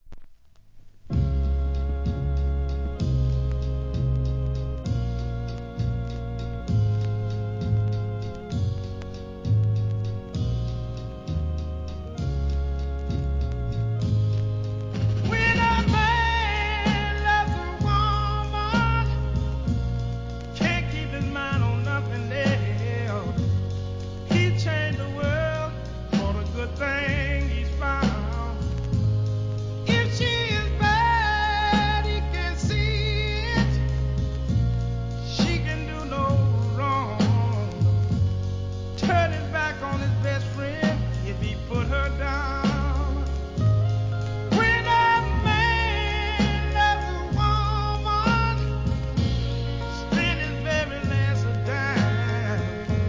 ¥ 1,320 税込 関連カテゴリ SOUL/FUNK/etc...